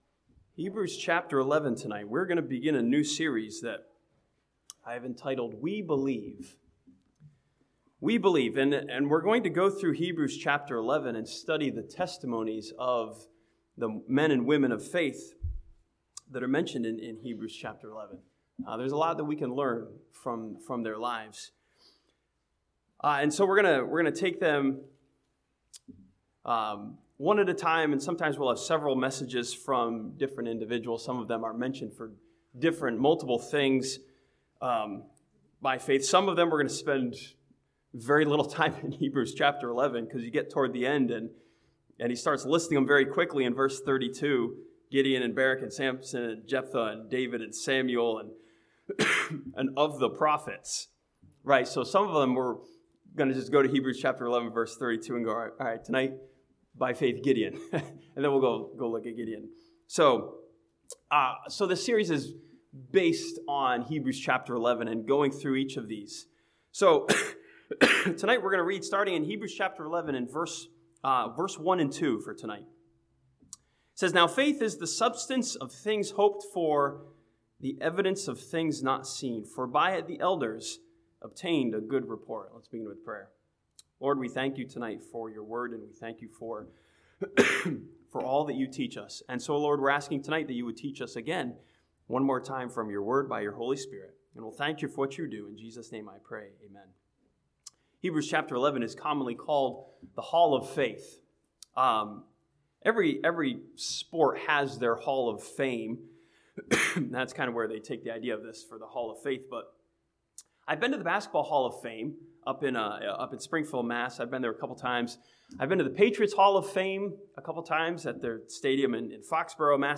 This sermon from Hebrews chapter 11 challenges Christians to believe like the men and women of this chapter.